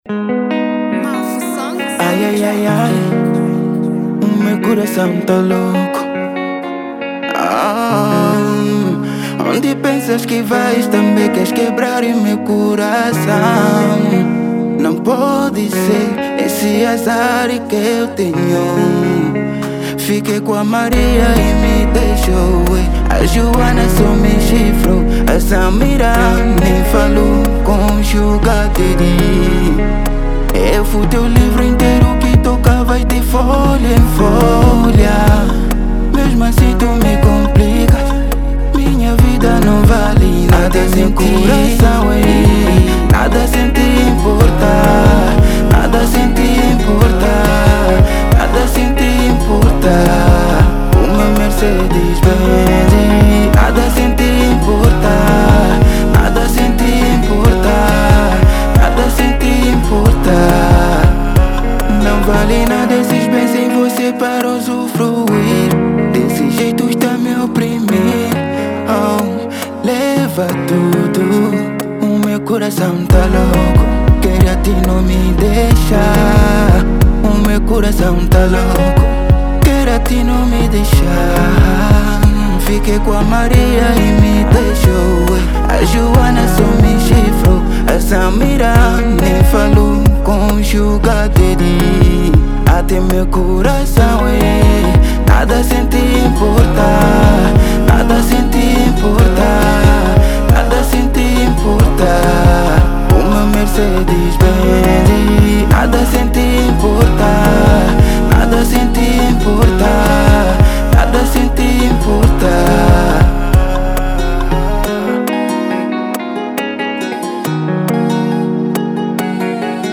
Kizomba